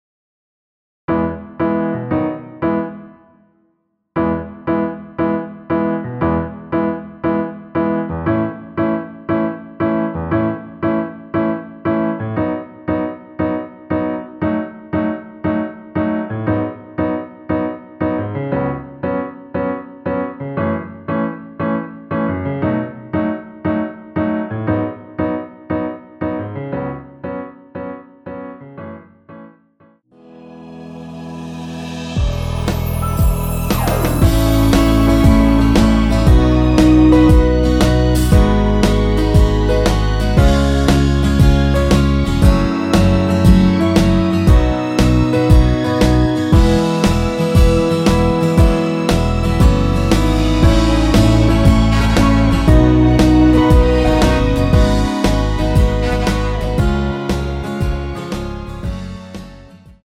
전주 없이 시작하는 곡이라 전주 만들어 놓았습니다.(미리듣기 참조)
원키에서(-2)내린 MR입니다.
앞부분30초, 뒷부분30초씩 편집해서 올려 드리고 있습니다.